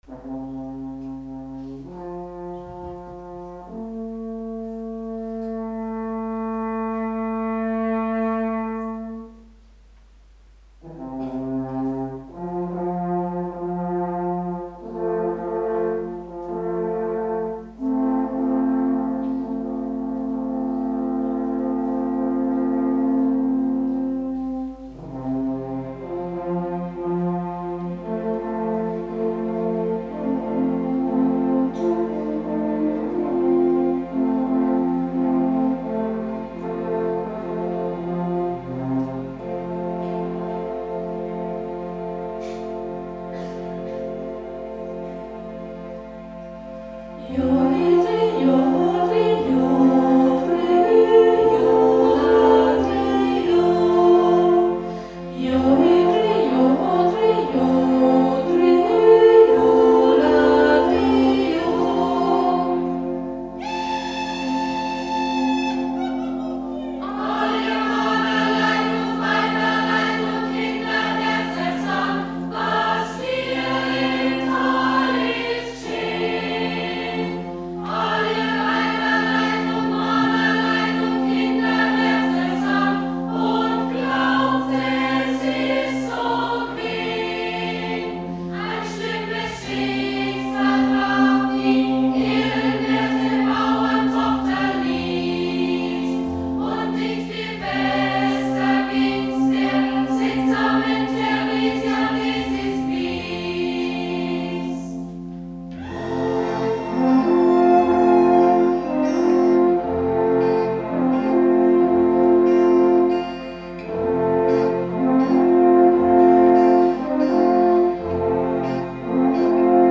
ein bairisches Musical
In dem Prolog berichtet der Dreig’sang abwechselnd mit dem Chor  von dem schlimmen Schicksal der Holler Theresia und der Feichtbauer Lies, die den gleichen Mann den Feichtbauer Martin geliebt hatten.